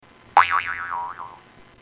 Boing Sound